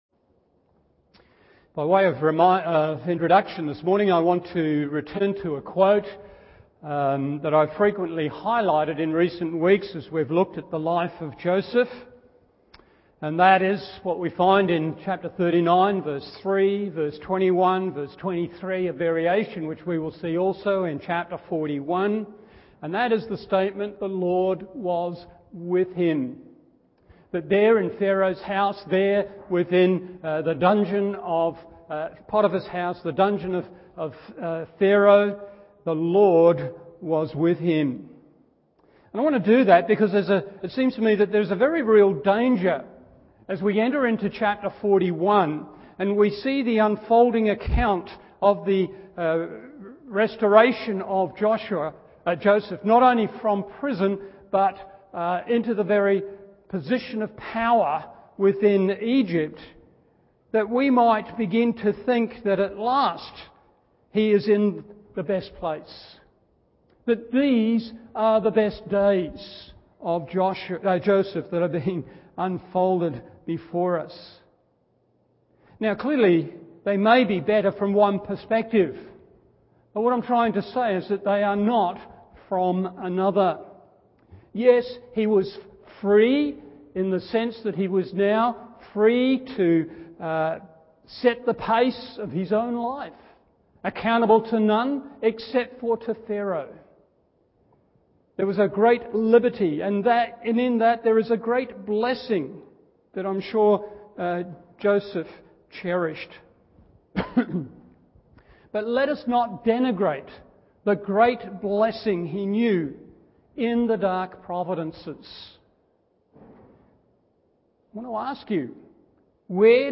Morning Service Genesis 41:1-36 1.